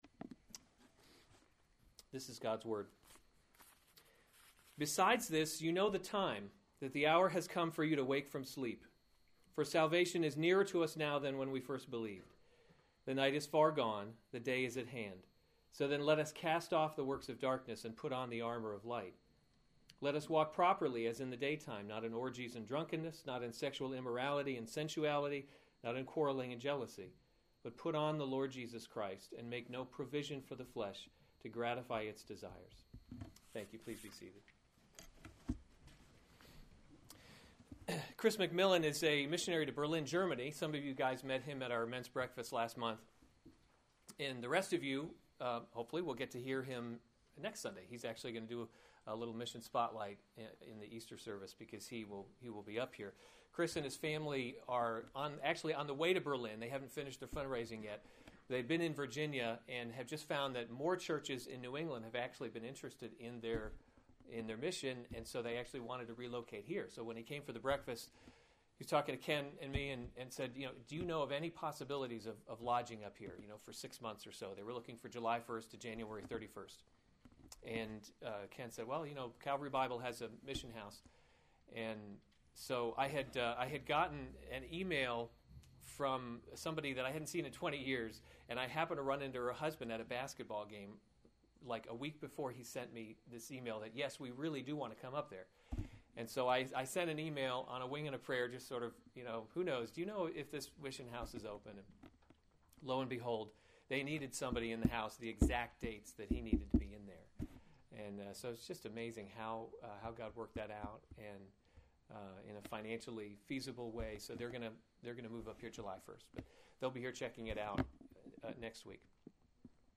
March 28, 2015 Romans – God’s Glory in Salvation series Weekly Sunday Service Save/Download this sermon Romans 13:11-14 Other sermons from Romans 11 Besides this you know the time, that the […]